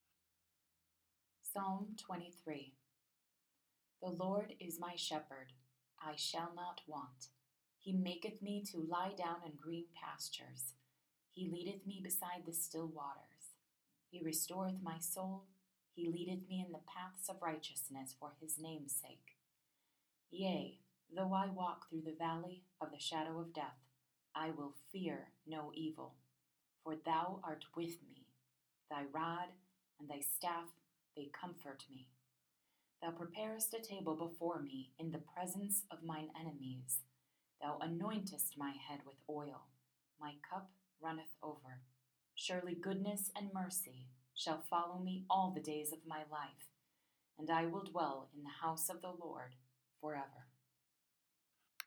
I recorded a few Psalms.